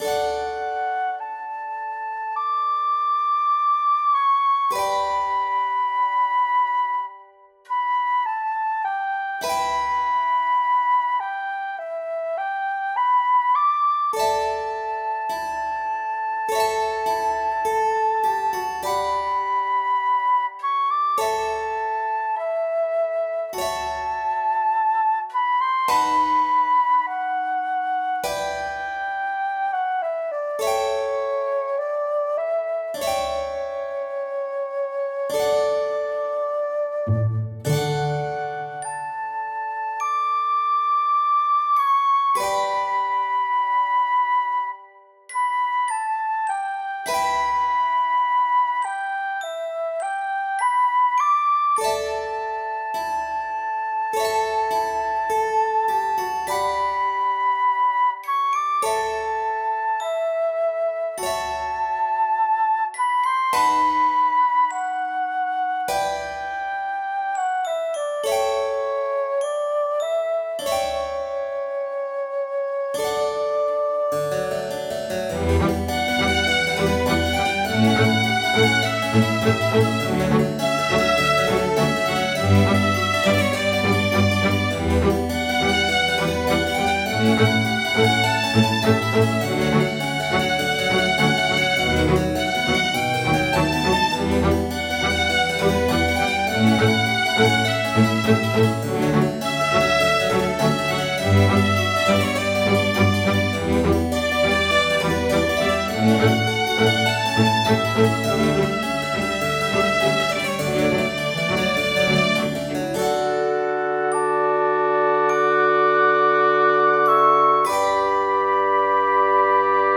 フリーBGM素材- ちょっと田舎の教会って感じ。
ちょっと田舎の教会って感じ。